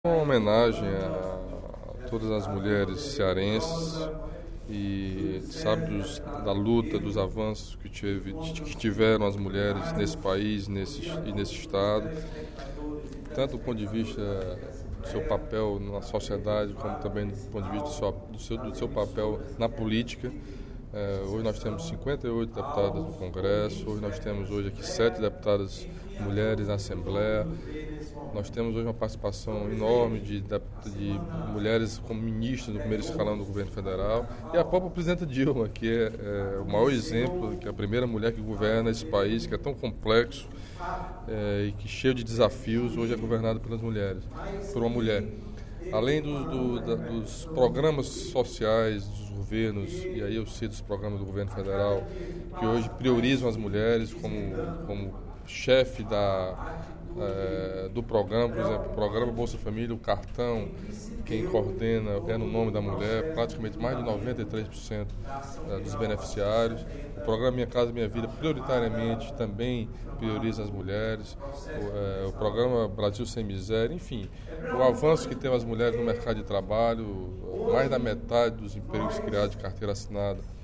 Durante o primeiro expediente da sessão plenária desta sexta-feira (07/03), o deputado Camilo Santana (PT) elogiou a luta e a participação das mulheres em programas sociais, de educação e na vida política do País.